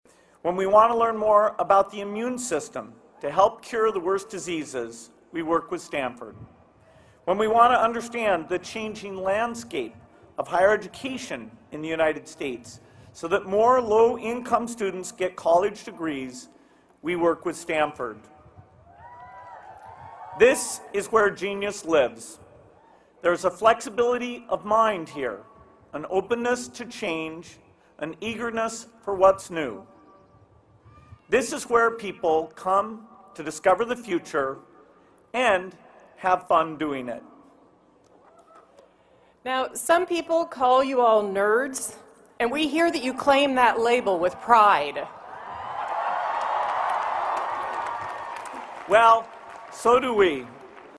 公众人物毕业演讲第21期:比尔盖茨夫妇于斯坦福大学(2) 听力文件下载—在线英语听力室